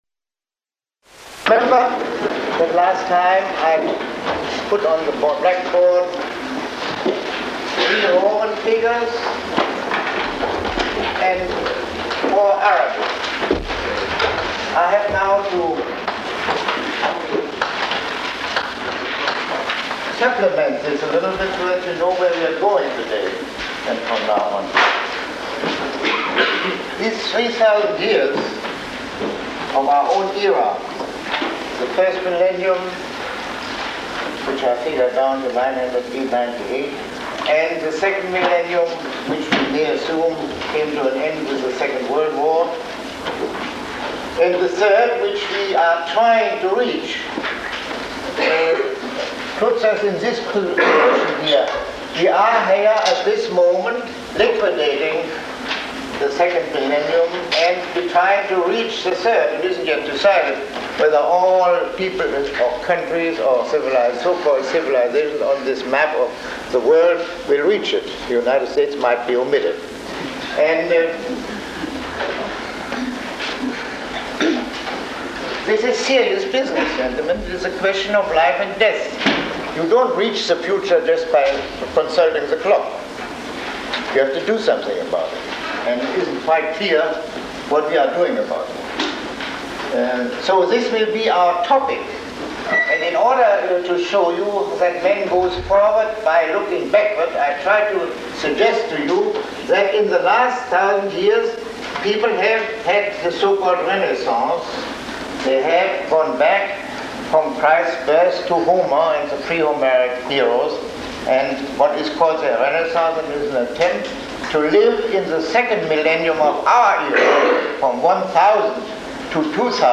Lecture 04